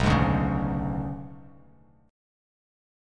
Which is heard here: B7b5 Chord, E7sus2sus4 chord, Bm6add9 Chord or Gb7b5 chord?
Bm6add9 Chord